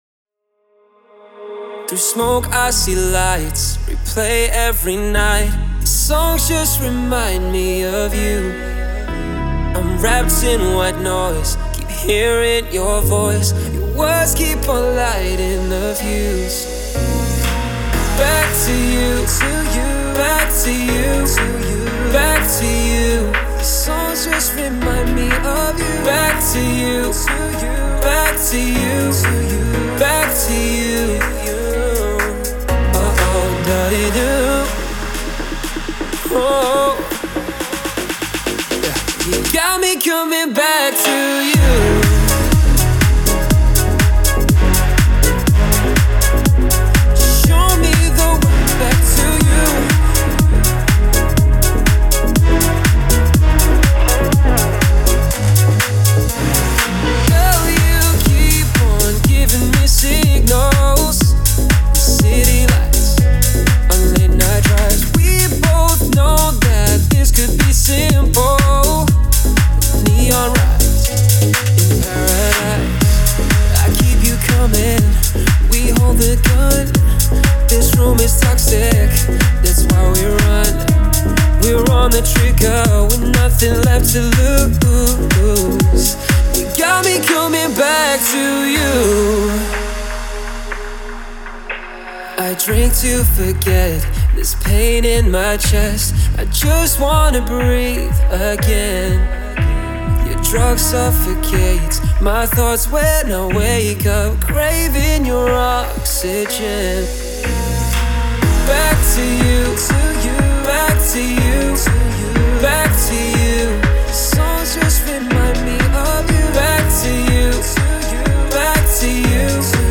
это трек в жанре поп с элементами инди